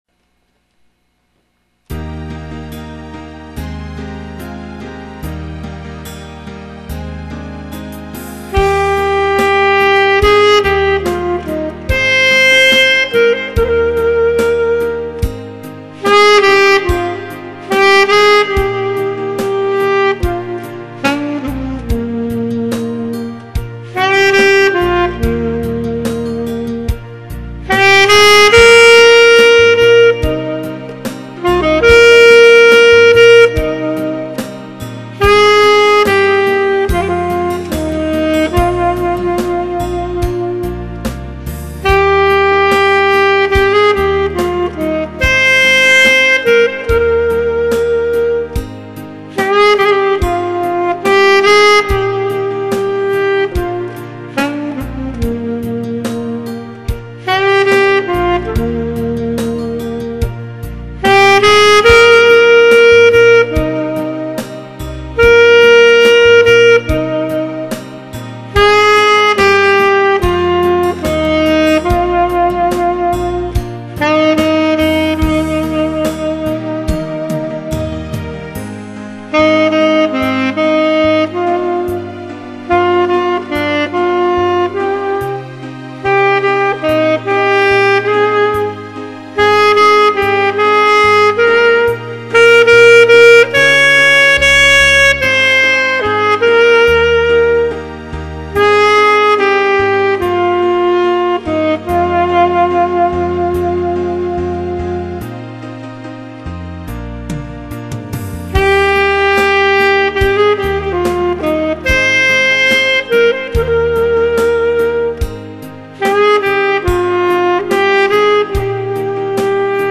아직도 박자감이 많이 부족하여 박자를 몇군데 놓친곳이 있습니다.